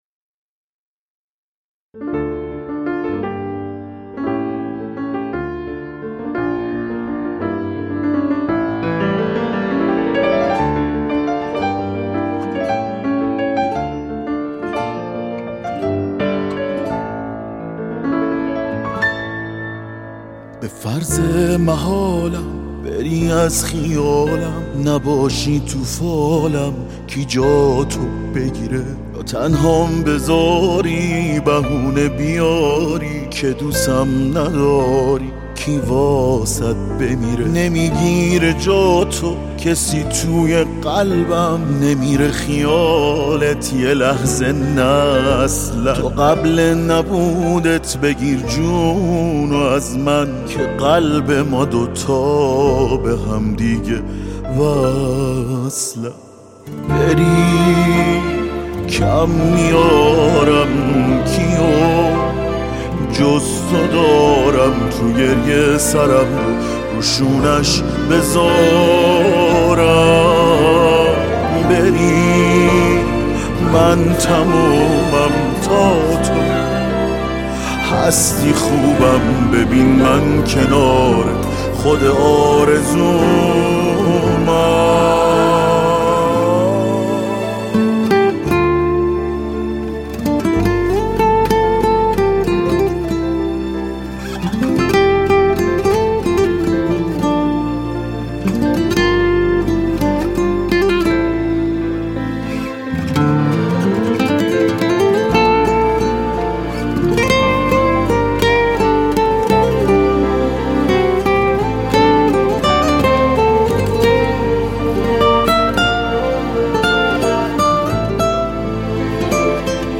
یه البوم كاملا پائيزى …